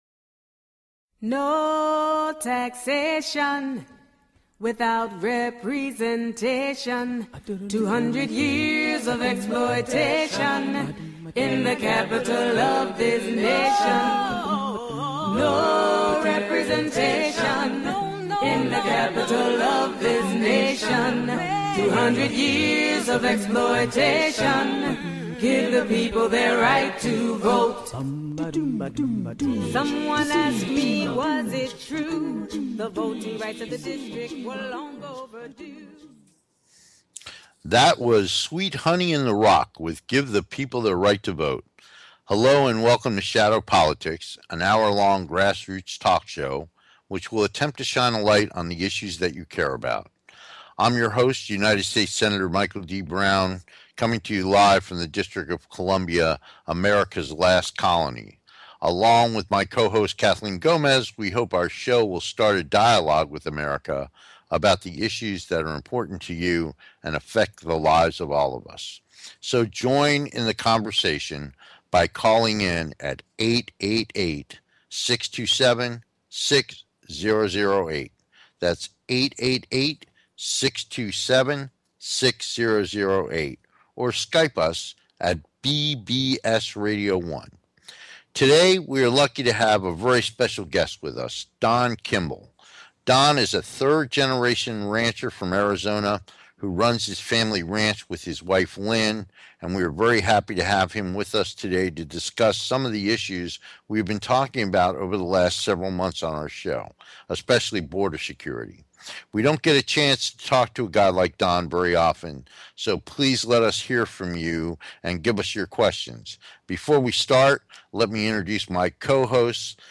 Talk Show Episode
Shadow Politics is a grass roots talk show giving a voice to the voiceless.